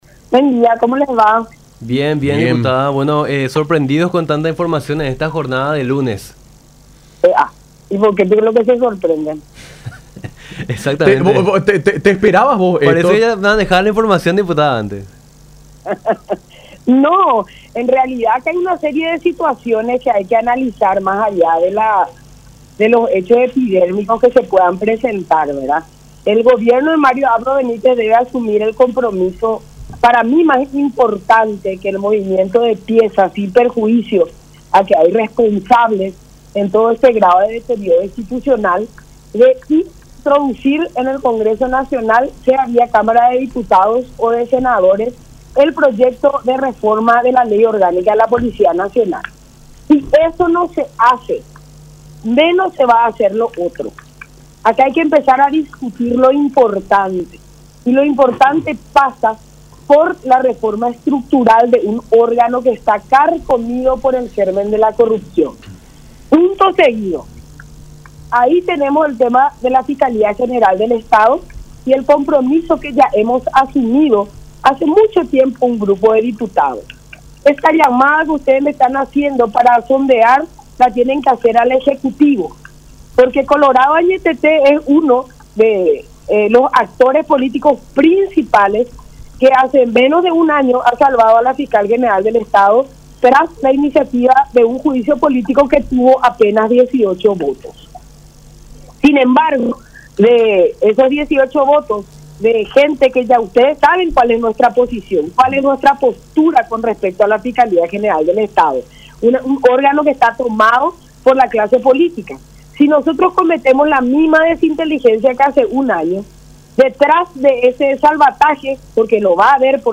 Kattya González, diputada del PEN.
07-KATTYA-GONZALEZ.mp3